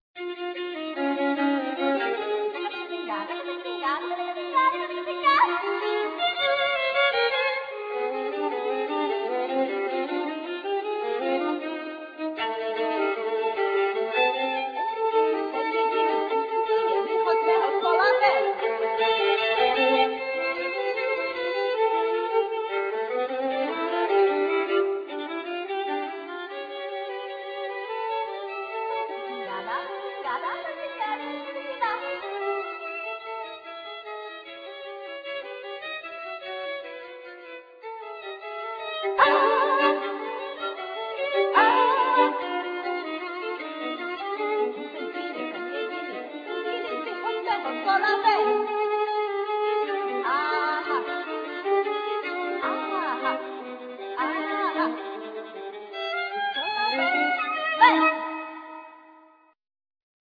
Violin, Voice